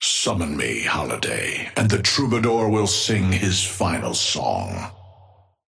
Amber Hand voice line - Summon me, Holliday, and the Troubadour will sing his final song.
Patron_male_ally_astro_start_05.mp3